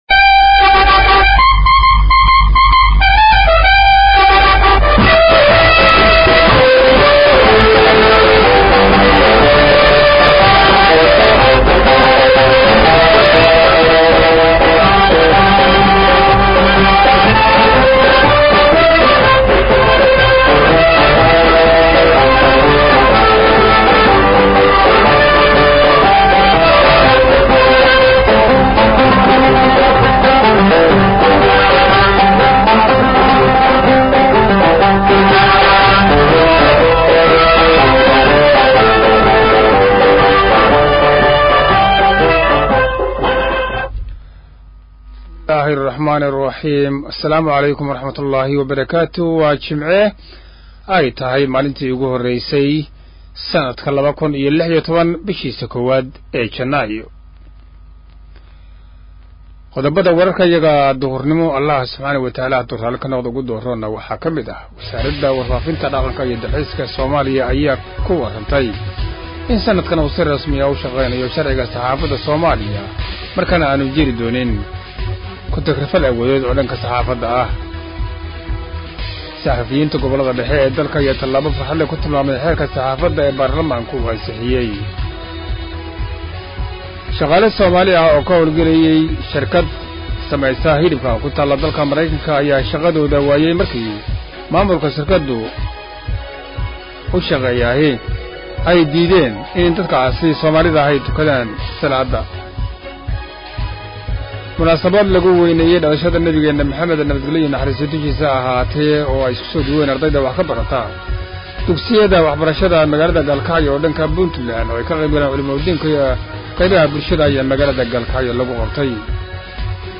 Dhageyso warka Duhur ee Radio Muqdisho